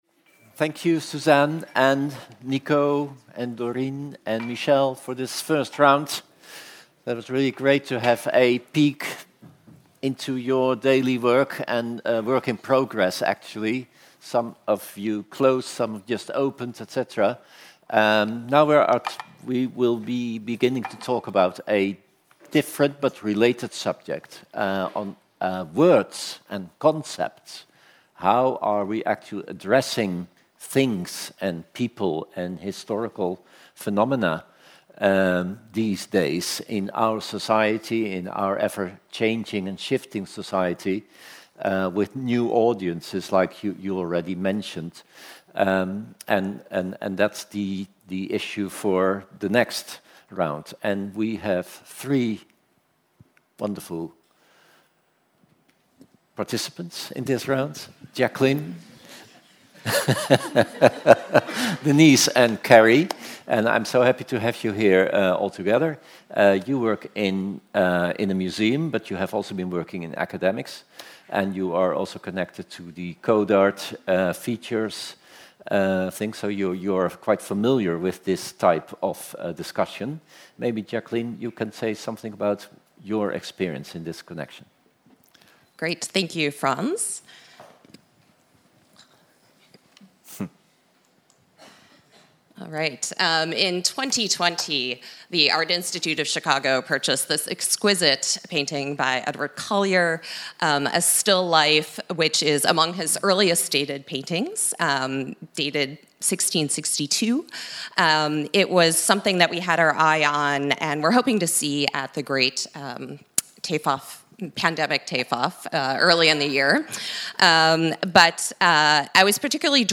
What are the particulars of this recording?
Saturday, 4 June 2022 — 15:45-17:15 Rijksmuseum, Amsterdam